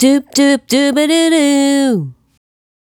Doodoodooba 085-C#.wav